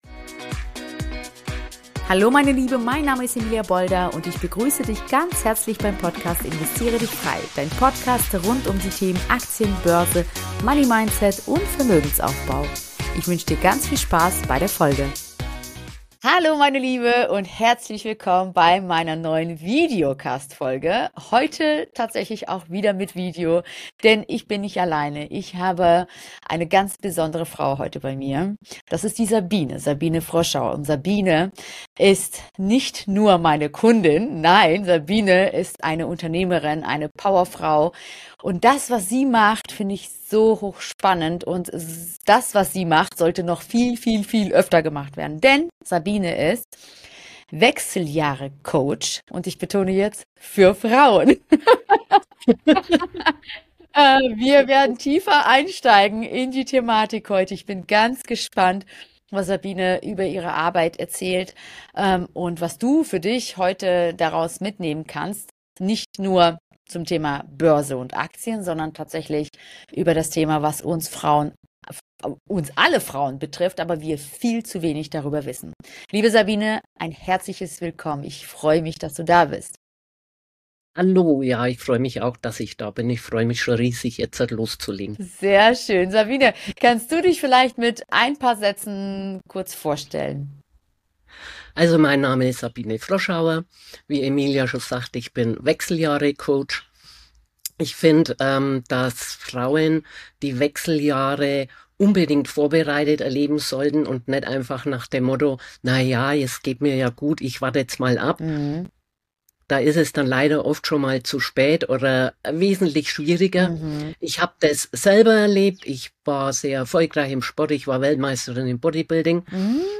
#62 Finanzen und Gesundheit in den Wechseljahren - Das Interview